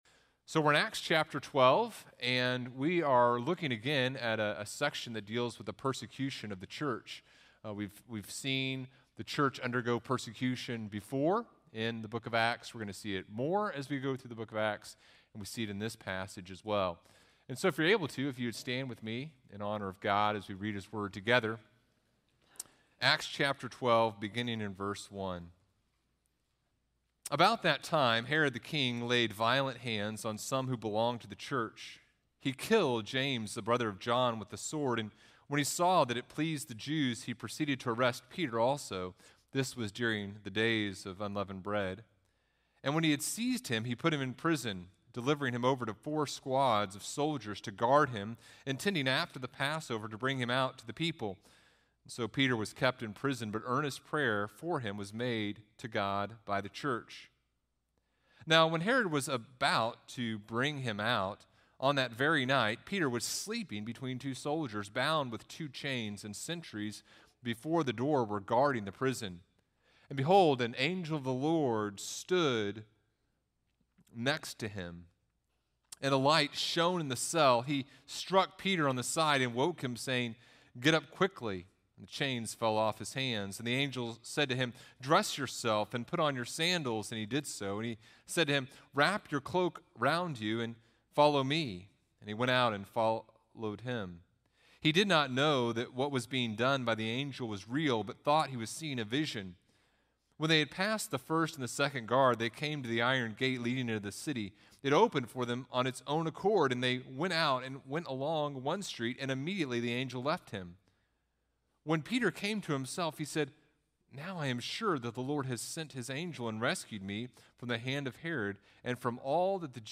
Sermon Series Archives - Listen to Sermons from Bethany Community Church in Washington, IL